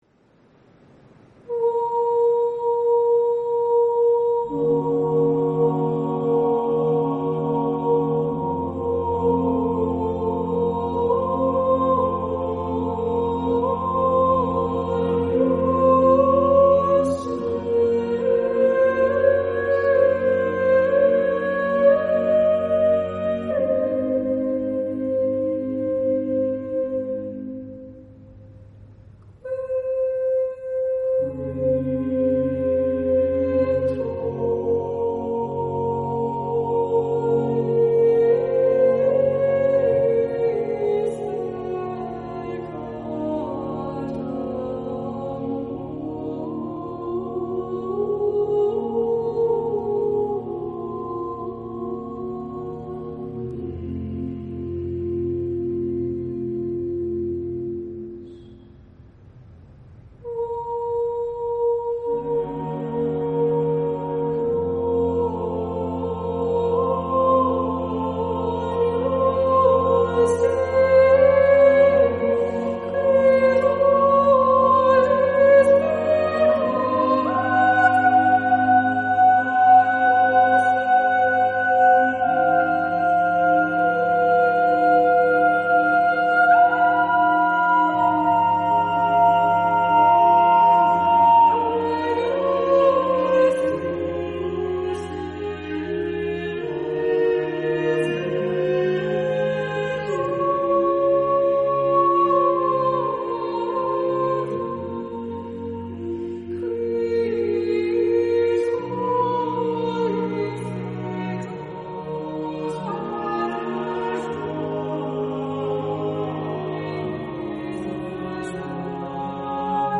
That music lifts us up towards the heavens.